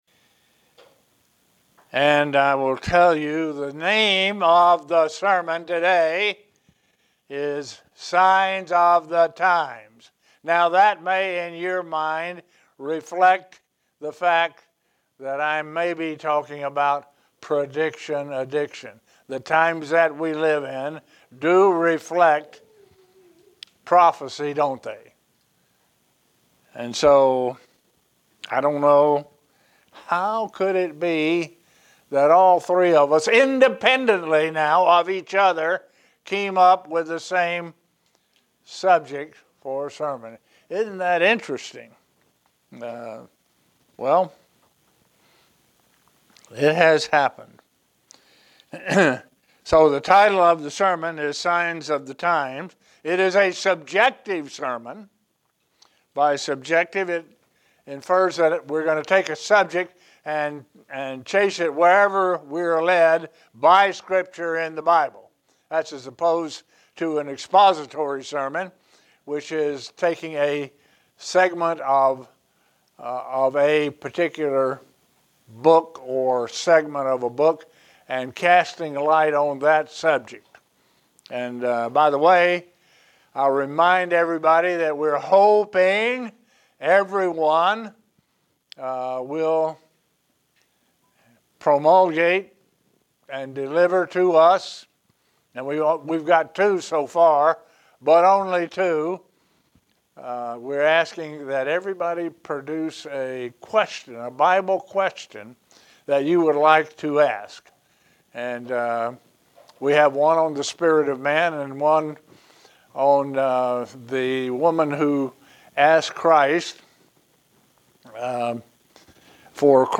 A sermon that relates our time to signs that are talked about in the Bible.